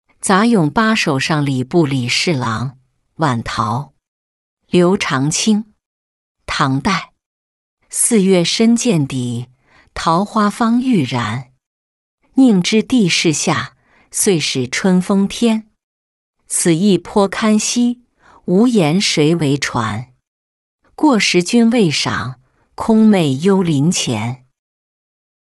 杂咏八首上礼部李侍郎·晚桃-音频朗读